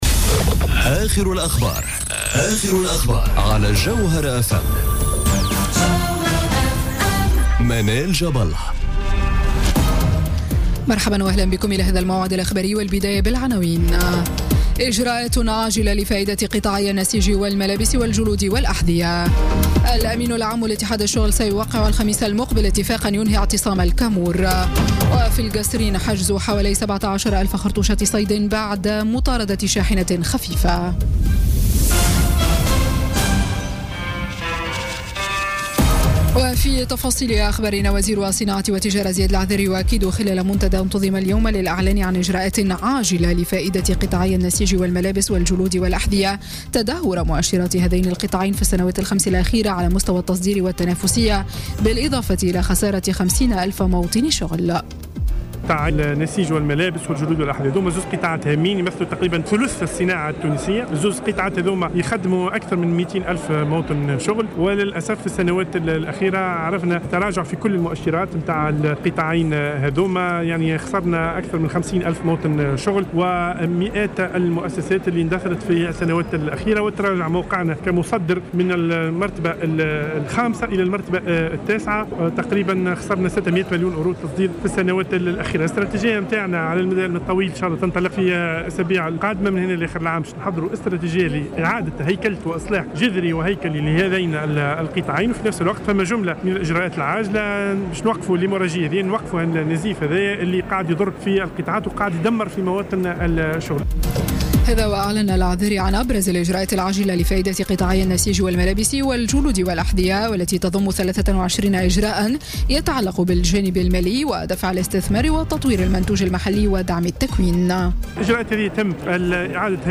نشرة أخبار السادسة مساء ليوم الاثنين 12 جوان 2017